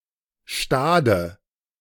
Stade (German pronunciation: [ˈʃtaːdə]
De-Stade.ogg.mp3